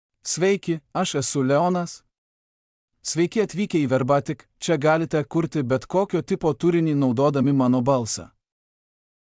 Leonas — Male Lithuanian (Lithuania) AI Voice | TTS, Voice Cloning & Video | Verbatik AI
Leonas is a male AI voice for Lithuanian (Lithuania).
Voice sample
Male
Leonas delivers clear pronunciation with authentic Lithuania Lithuanian intonation, making your content sound professionally produced.